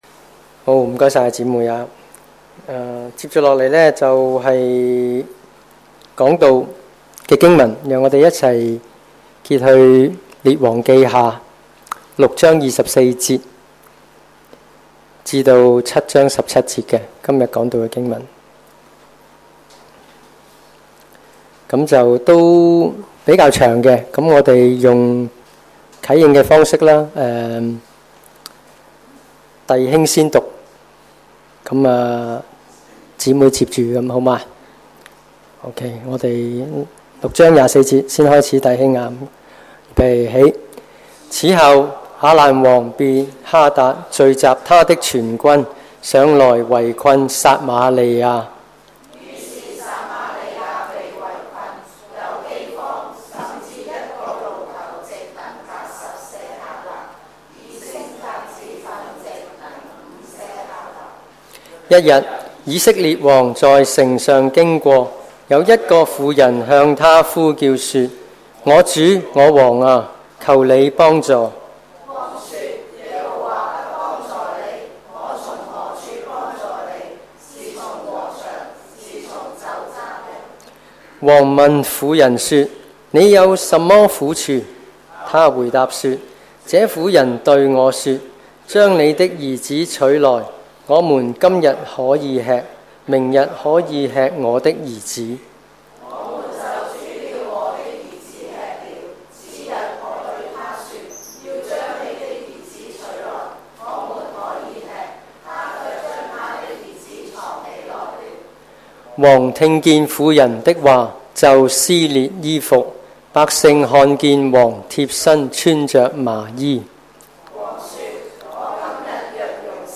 主日崇拜講道 – 你信絕處也可逢生？